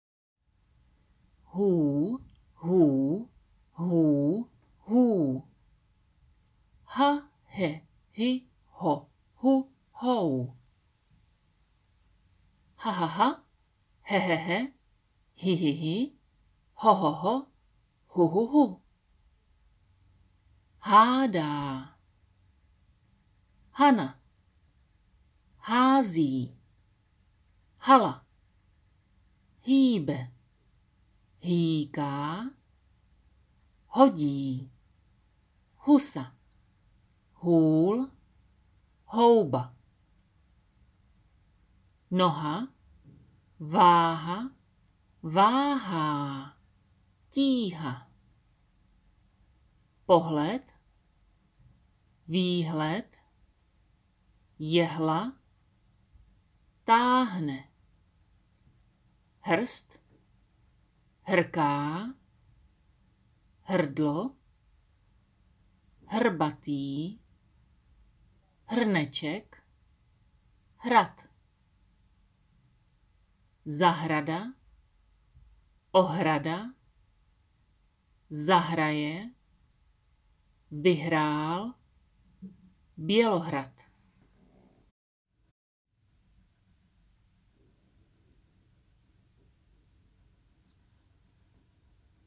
Tady si můžete stáhnout nahrávku na výslovnost H – slova